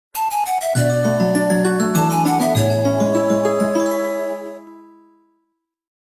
короткие
добрые
Отличная мелодия на смс для вашего телефона